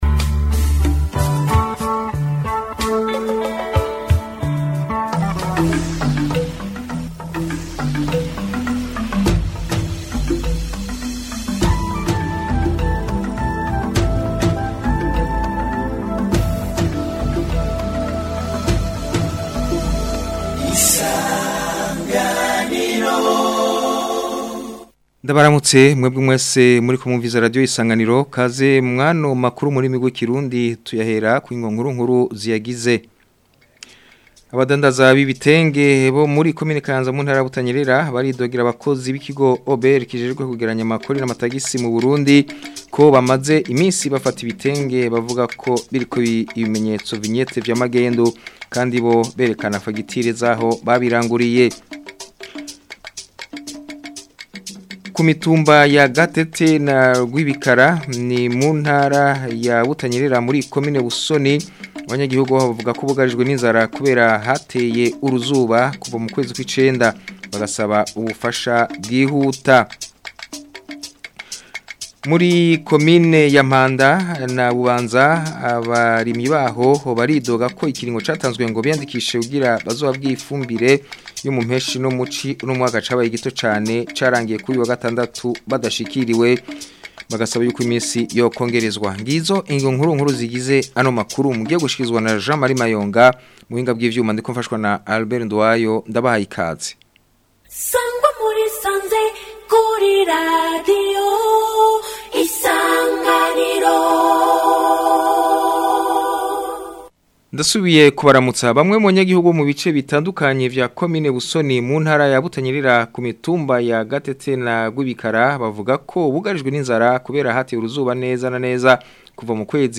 Amakuru yo ku wa 19 Nzero 2026